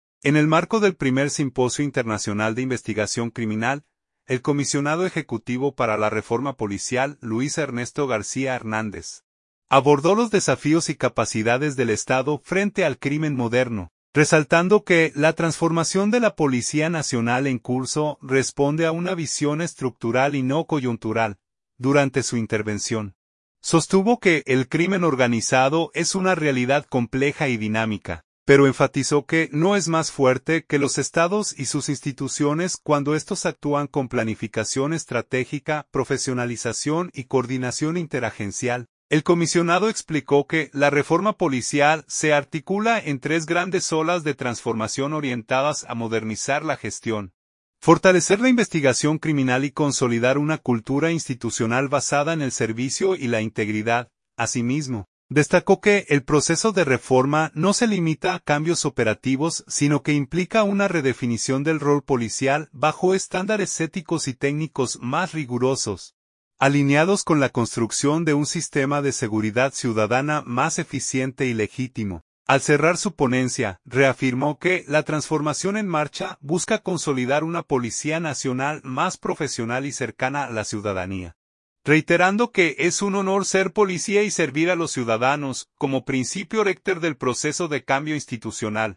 En el marco del Primer Simposio Internacional de Investigación Criminal, el comisionado ejecutivo para la Reforma Policial, Luis Ernesto García Hernández, abordó los desafíos y capacidades del Estado frente al crimen moderno, resaltando que la transformación de la Policía Nacional en curso responde a una visión estructural y no coyuntural.